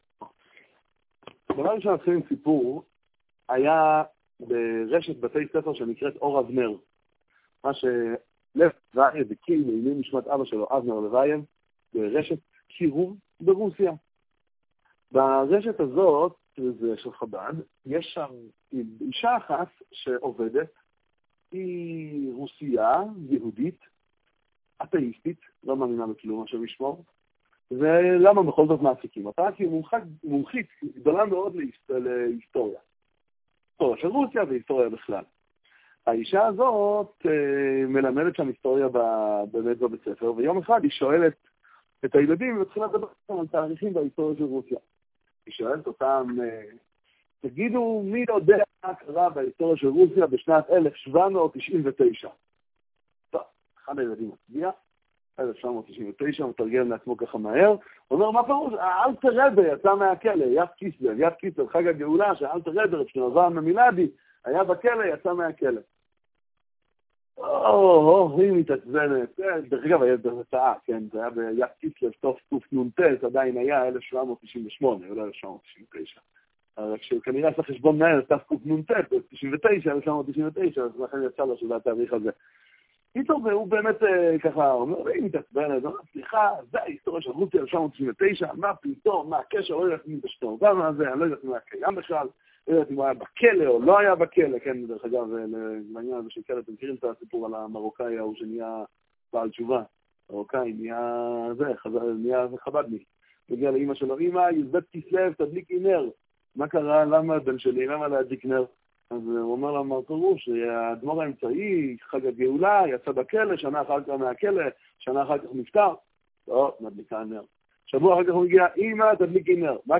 דבר תורה קצר לפרשת תרומה